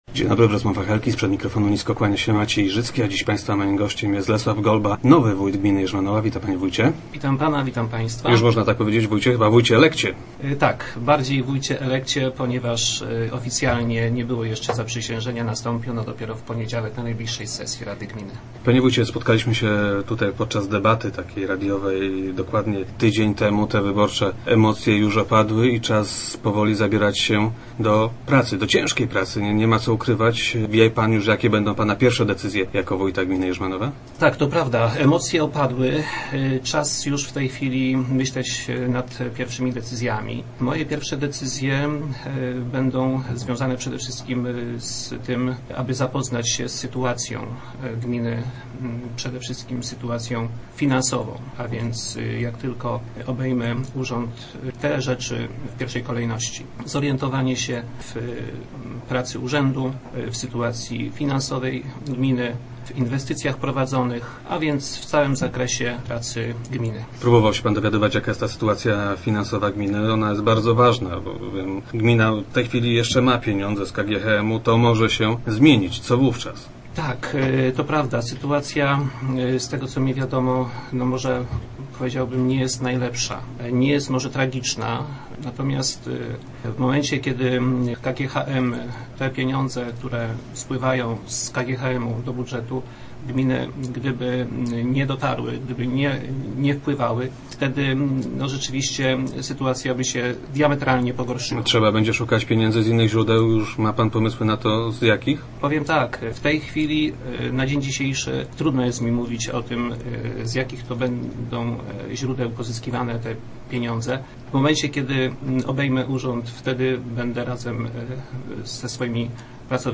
1203_golba.jpg- Zdaję sobie sprawę z trudności jakie mnie czekają – powiedział w Rozmowach Elki Lesław Golba, nowy wójt Jerzmanowej.